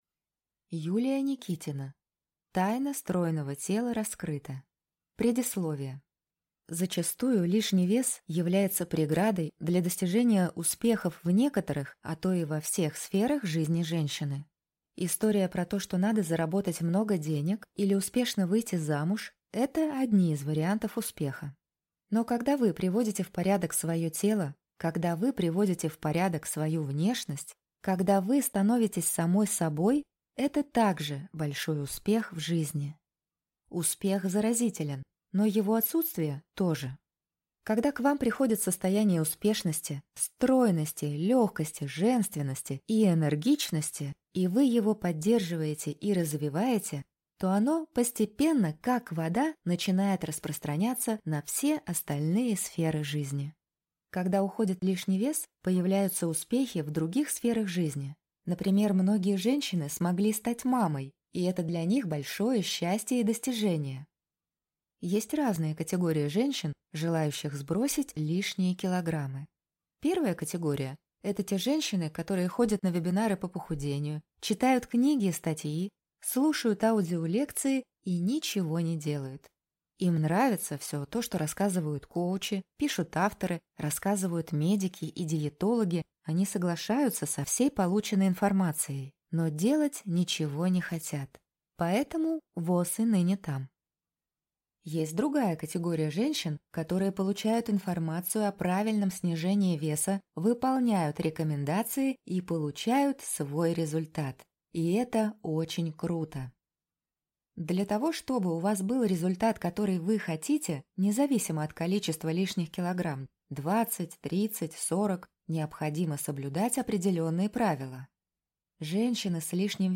Аудиокнига Тайна стройного тела раскрыта | Библиотека аудиокниг